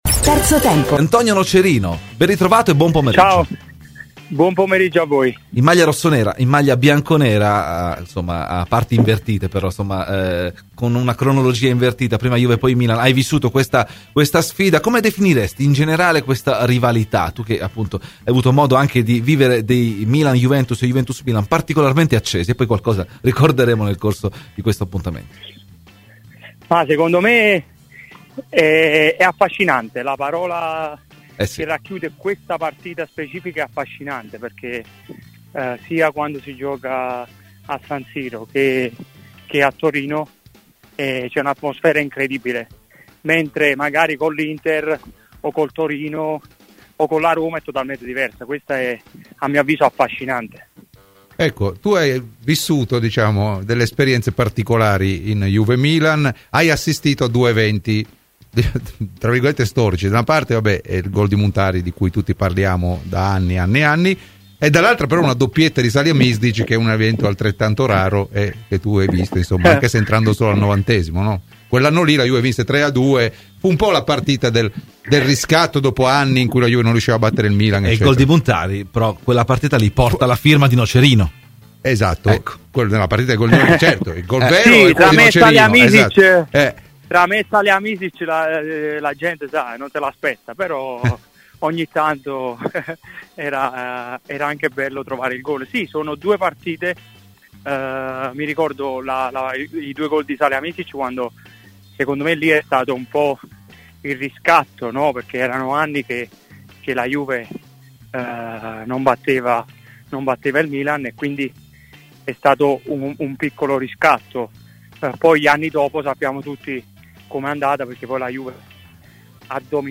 Ai microfoni di Radio Bianconera, nel corso di ‘Terzo Tempo’, è intervenuto Antonio Nocerino, doppio ex di Juventus e Milan: